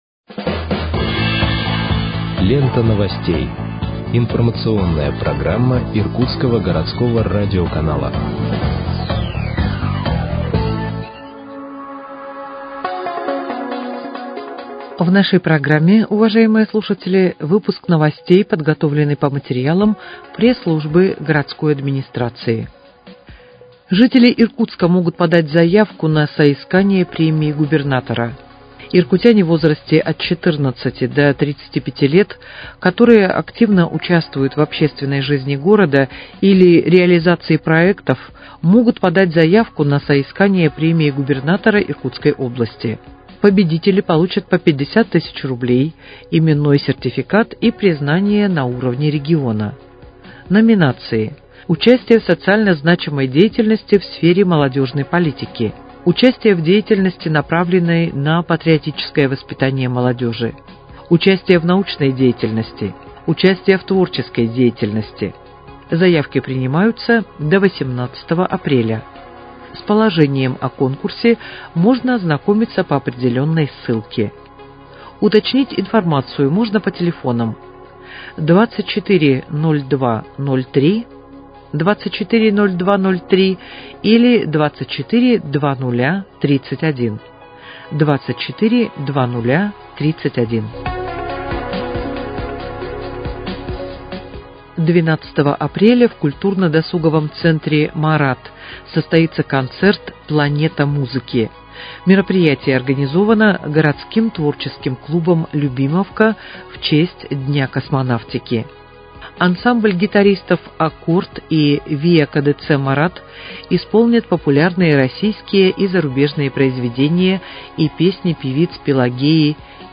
Выпуск новостей в подкастах газеты «Иркутск» от 11.04.2025 № 2